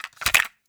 CASSETTE_RATTLE_20.wav